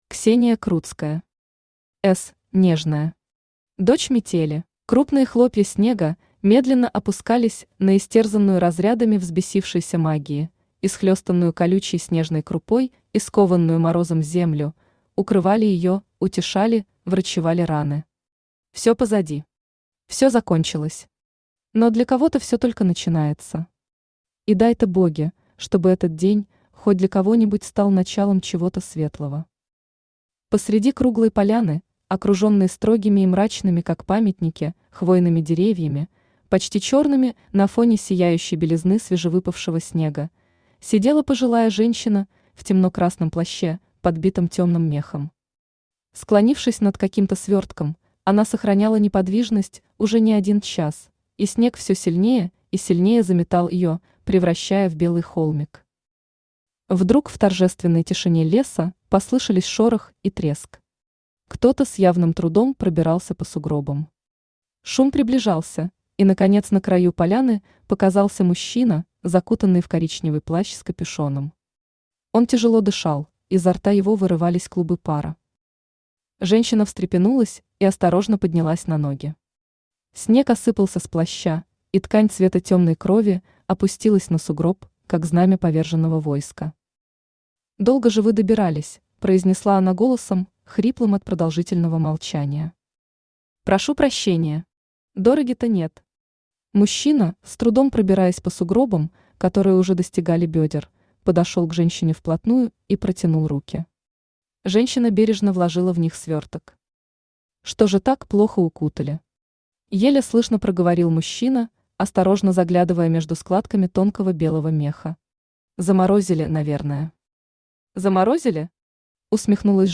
Аудиокнига (С)нежная. Дочь метели | Библиотека аудиокниг
Дочь метели Автор Ксения Крутская Читает аудиокнигу Авточтец ЛитРес.